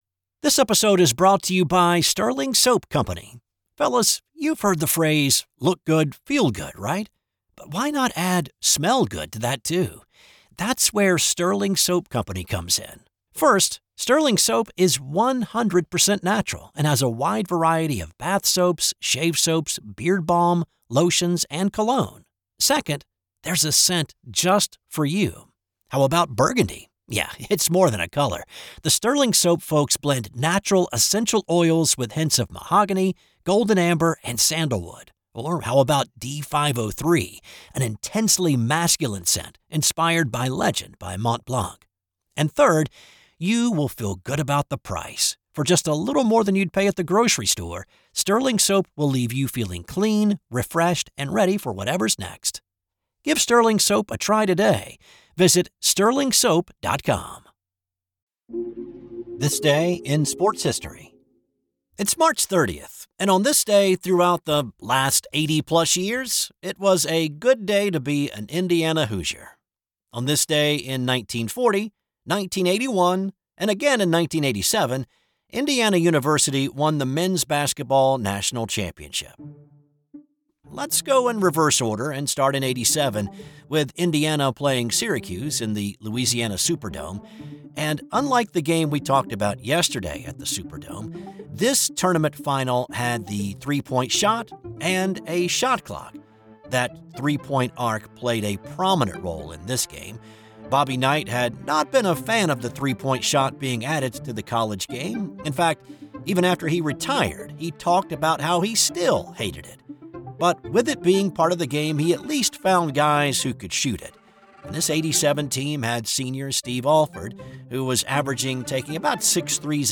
I research, write, voice, and produce each show.